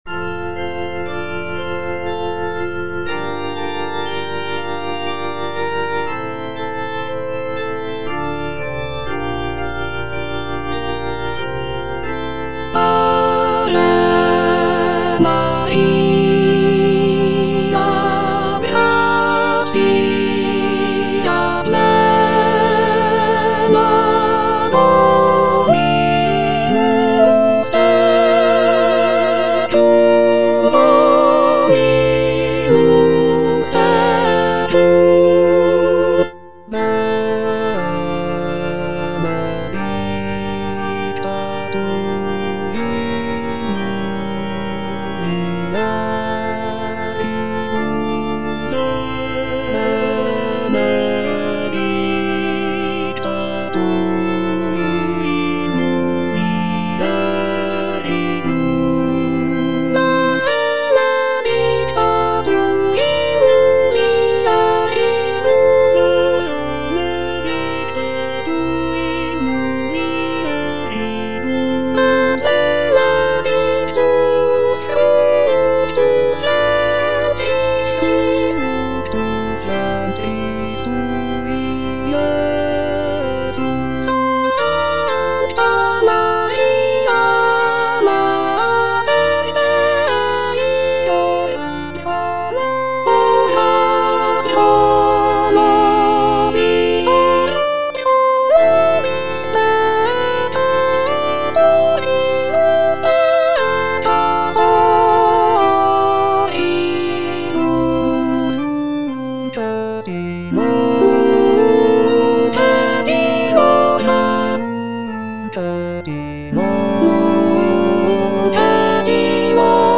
Soprano (Emphasised voice and other voices)
choral piece